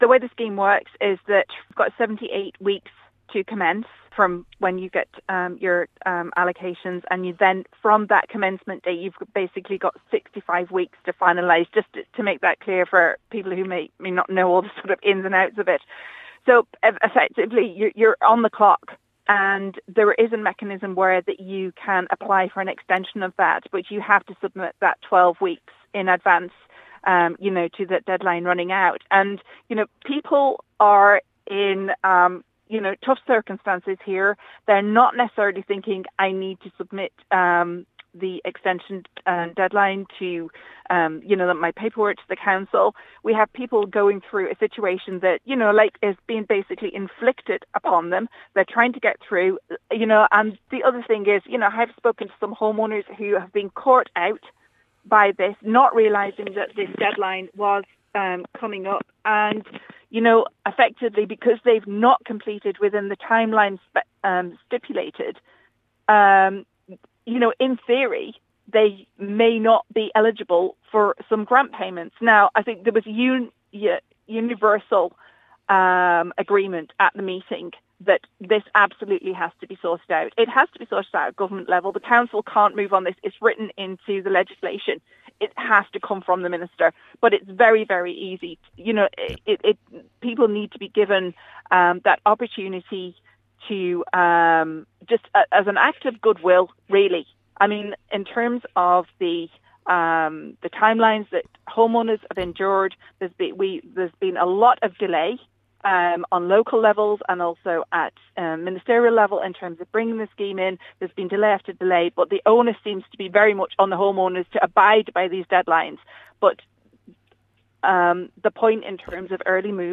a longer discussion